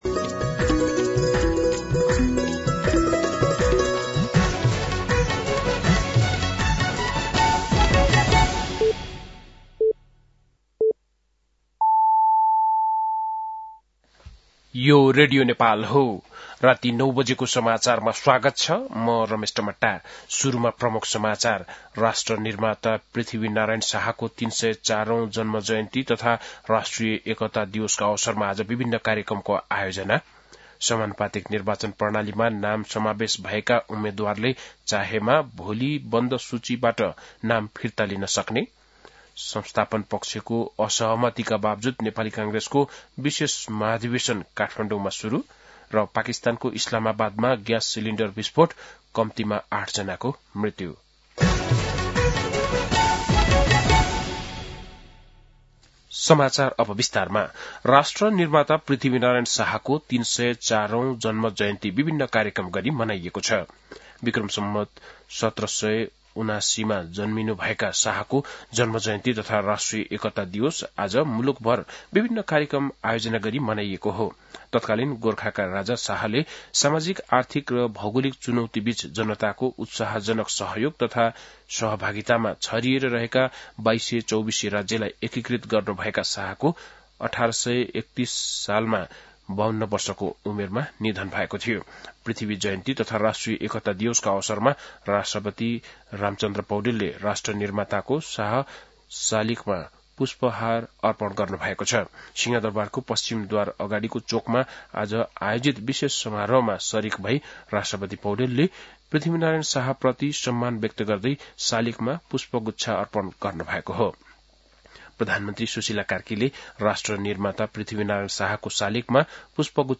बेलुकी ९ बजेको नेपाली समाचार : २७ पुष , २०८२
9-pm-nepali-news-9-27.mp3